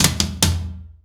ROOM TOM4C.wav